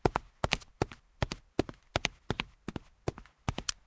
0002_脚步声.ogg